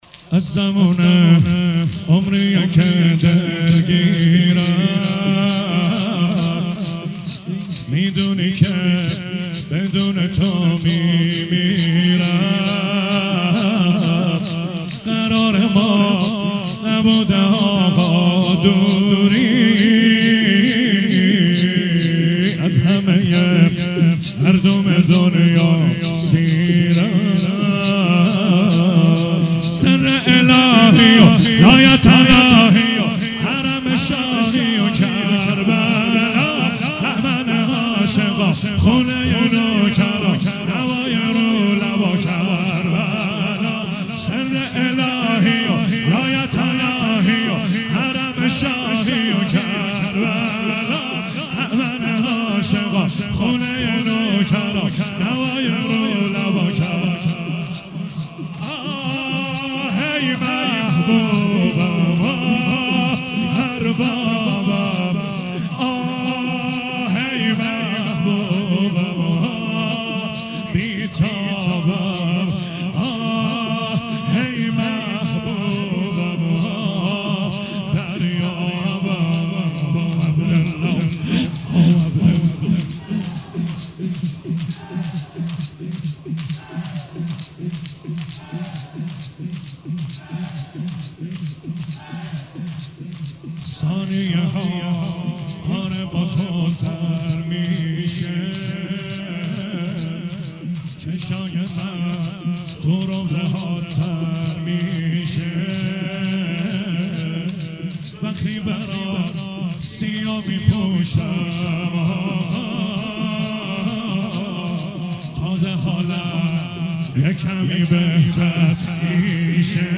شور - از زمونه عمریه که دلگیرم